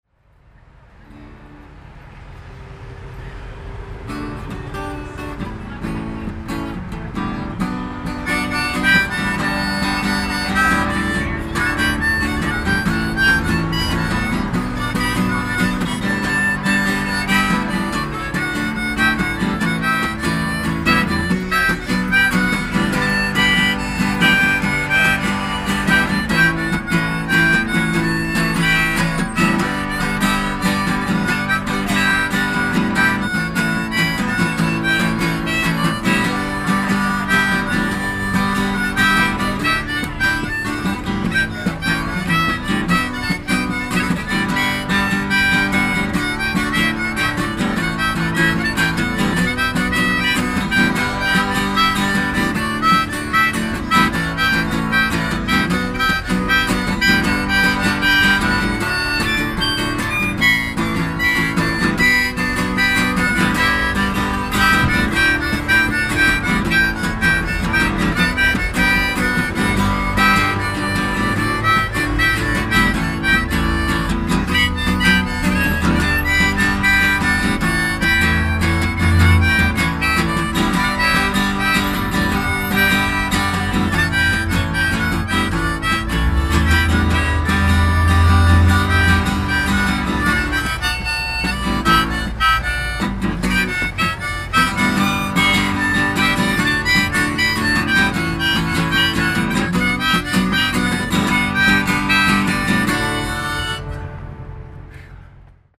but not his harmonica
busker